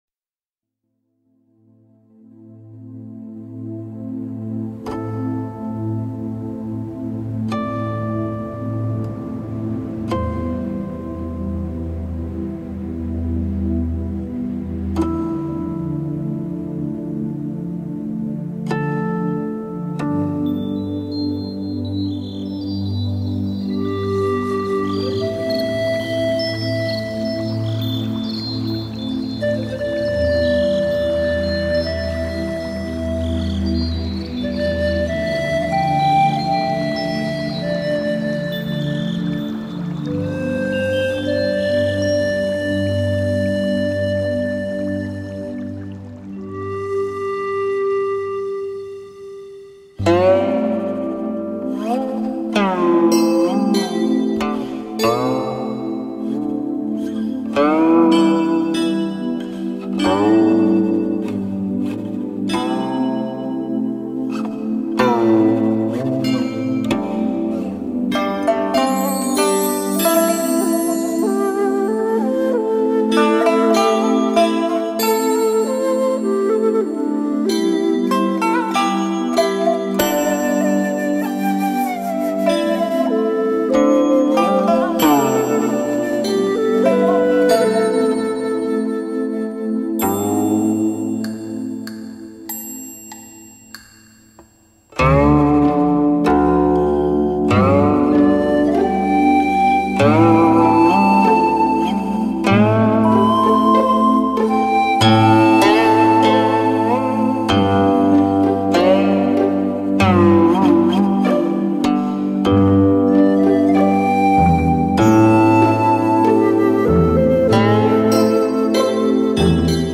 0001古琴禪修.mp3